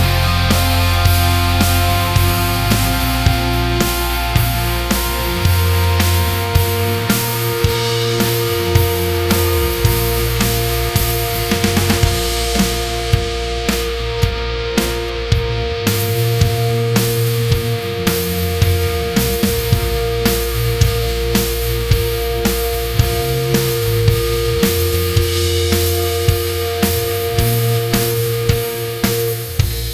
80s Power Ballad
80s_Power_Ballad_-_Small_mp3.mp3